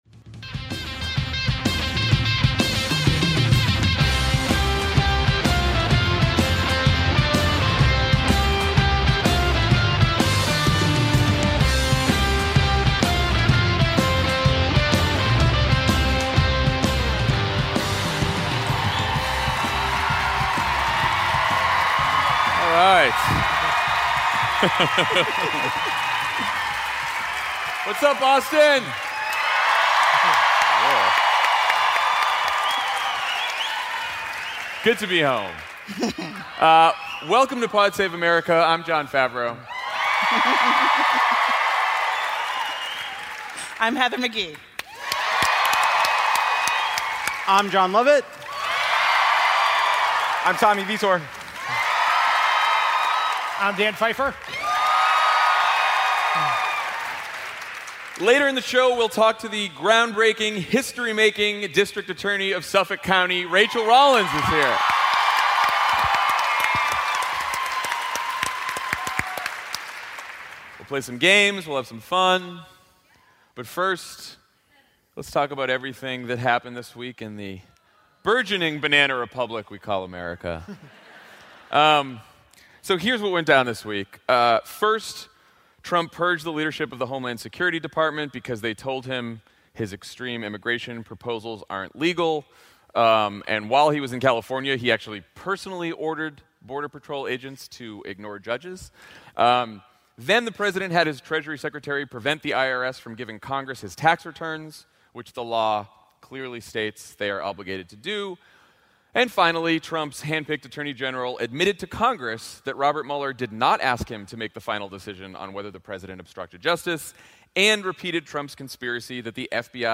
"Fascist cosplay." (LIVE in Boston)
Trump embraces lawlessness on immigration, congressional Democrats press for Trump's tax returns, and the 2020 candidates hit the trail in an economy that is growing while growing more unequal. Suffolk County District Attorney Rachael Rollins joins Jon, Jon, Tommy, Dan and Demos's Heather McGhee live on stage in Boston.